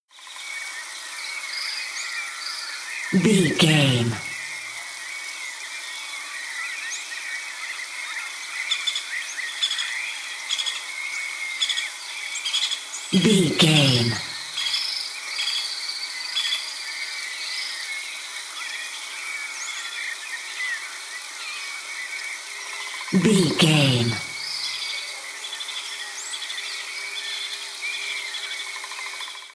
Rain forest ambience
Rain forest ambience 43
Sound Effects
calm
peaceful
repetitive